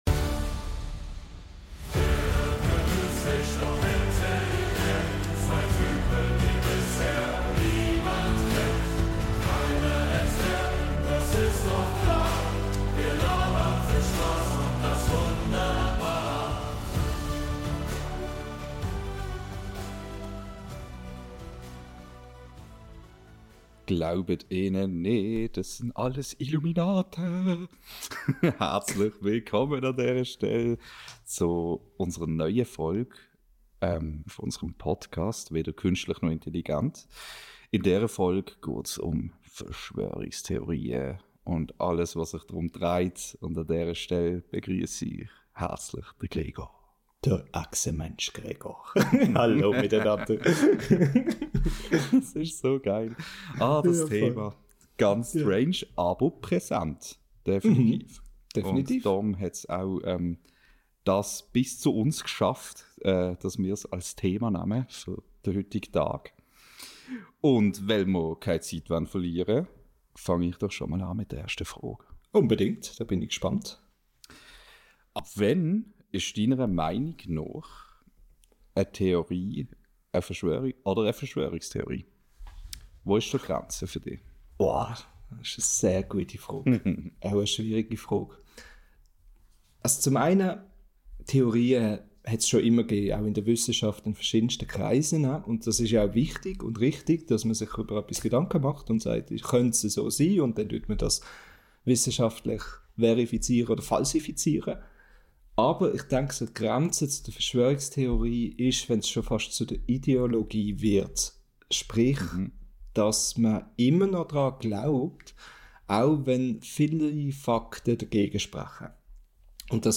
In dieser Episode unseres schweizerdeutschen Podcasts tauchen wir in die faszinierende Welt der Verschwörungstheorien ein.